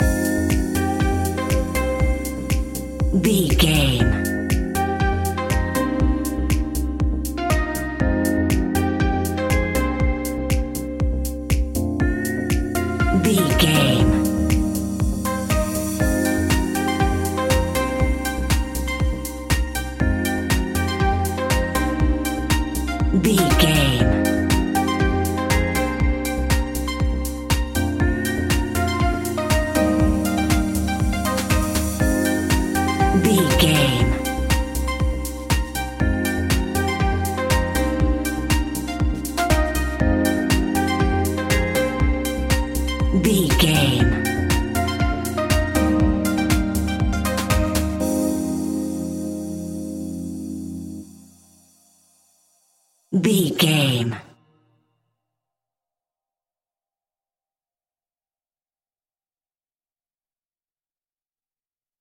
Aeolian/Minor
uplifting
driving
energetic
funky
synthesiser
drum machine
electro house
synth leads
synth bass